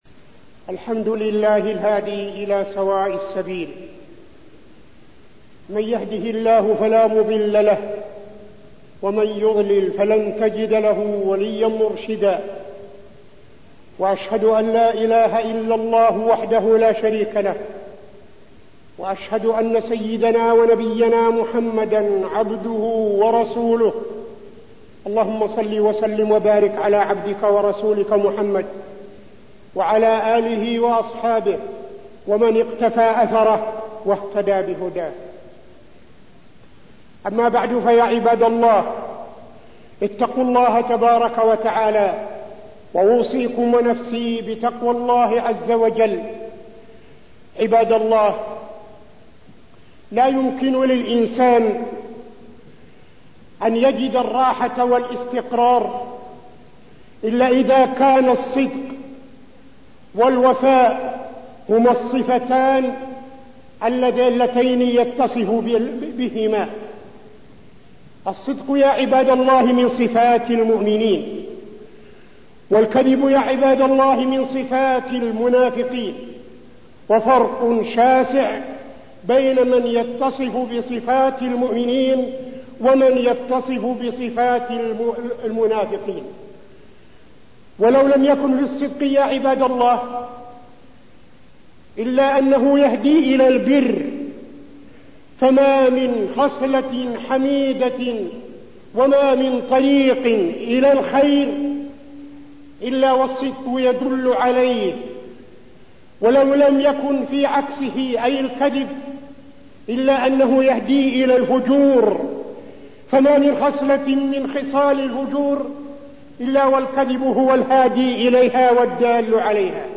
تاريخ النشر ٤ صفر ١٤٠٦ هـ المكان: المسجد النبوي الشيخ: فضيلة الشيخ عبدالعزيز بن صالح فضيلة الشيخ عبدالعزيز بن صالح الصدق